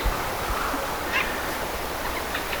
mikä rastaslaji?